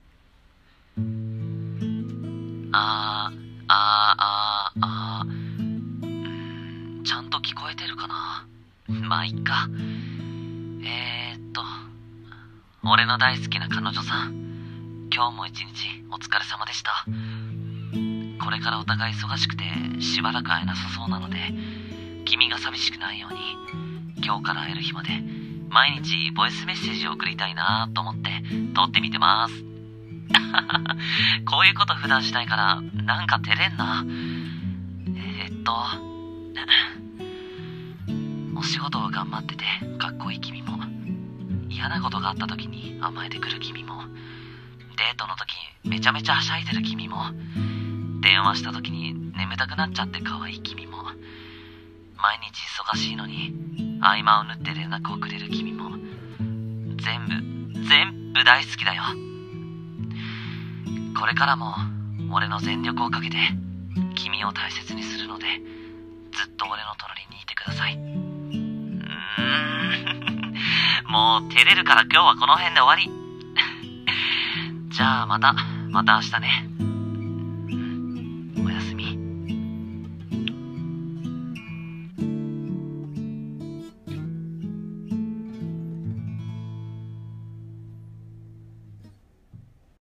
【声劇】ボイスメッセージ
癒し